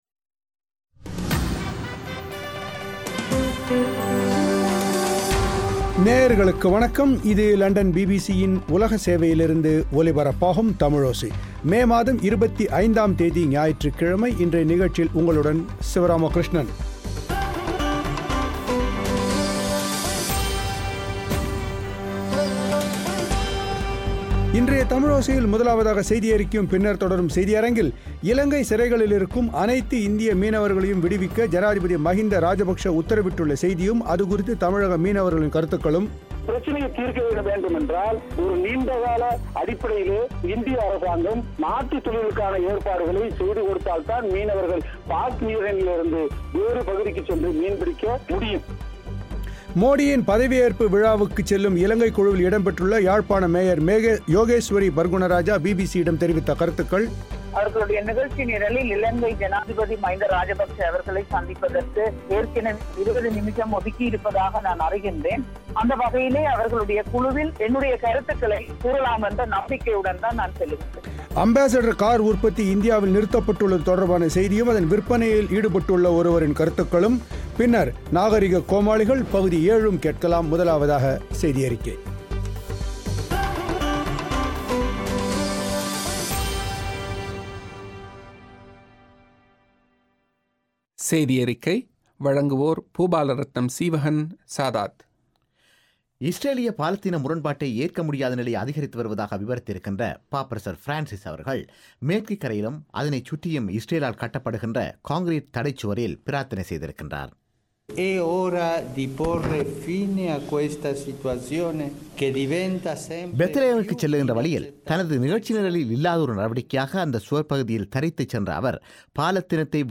மோடியின் பதவியேற்பு விழாவுக்குச் செல்லும் இலங்கைக் குழுவில் இடம்பெற்றுள்ள யாழ்ப்பாண மேயர் யோகேஸ்வரி பற்குணராஜாவின் பேட்டி